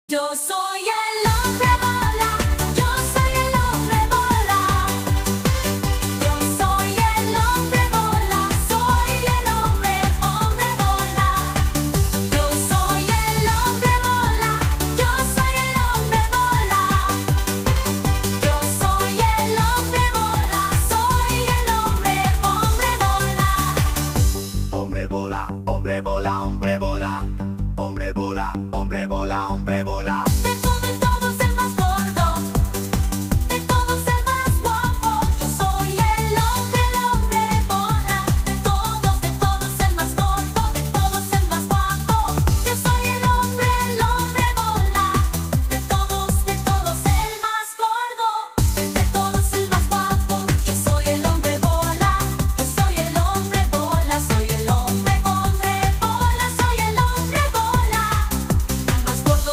BPM180
Audio QualityPerfect (Low Quality)
Seriously, here goes another meme whit AI cover. ups.